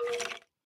sounds / mob / skeleton / say1.ogg